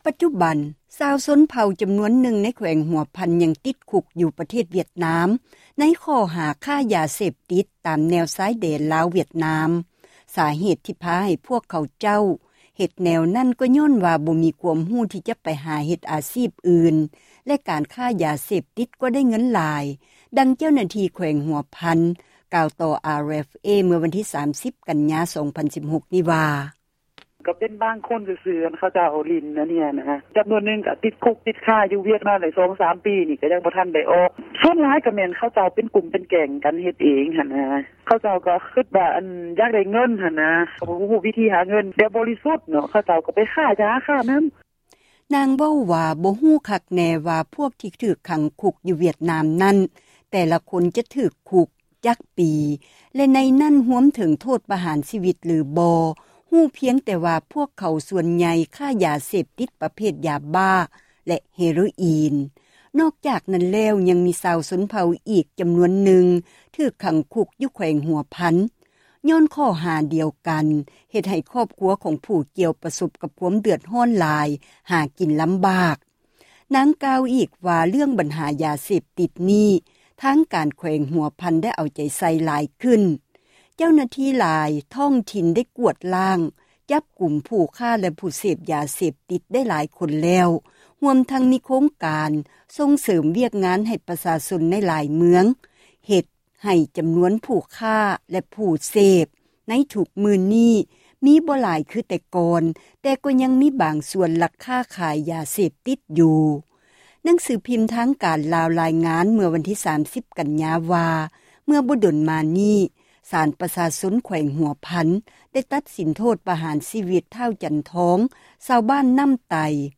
ສາເຫດ ທີ່ພາໃຫ້ ພວກຂະເຈົ້າ ເຮັດແນວນັ້ນ ກໍຍ້ອນ ບໍ່ມີຄວາມຮູ້ ທີ່ຈະໄປ ຫາເຮັດ ອາຊີບອື່ນ ແລະ ການຄ້າ ຢາເສພຕິດ ກໍ ໄດ້ເງິນຫຼາຍ, ດັ່ງ ເຈົ້າໜ້າທີ່ ແຂວງ ຫົວພັນ ກ່າວຕໍ່ ວິທຍຸ ເອເຊັຽເສຣີ ເມື່ອວັນທີ 30 ກັນຍາ 2016 ນີ້ວ່າ: